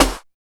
20 HIPHOP SN.wav